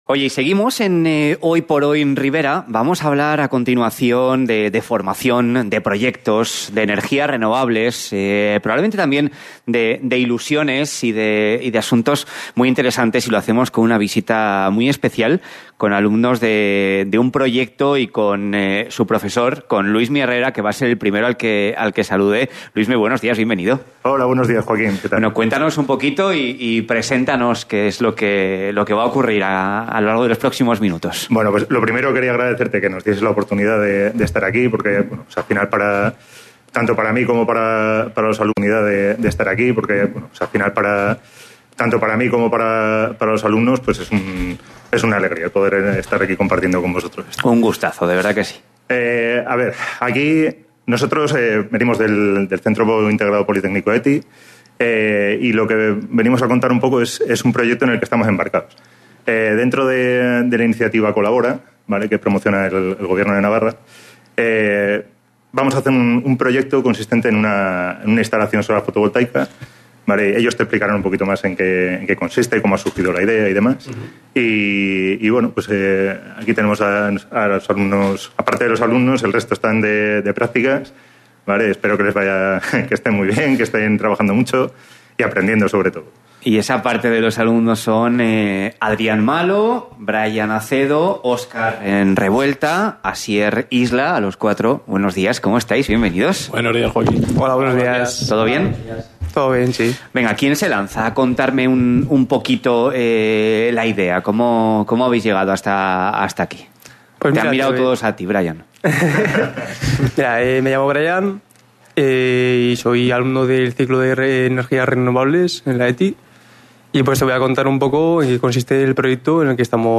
El pasado 9 de diciembre, el alumnado de 2º curso de Energías Renovables estuvo en la cadena SER Tudela realizando una entrevista al hilo del proyecto "SolarBike" de la iniciativa COLABORA que el CIP ETI Central está llevando a cabo.
entrevista-hoyporhoy_tudela-solar-bike-1-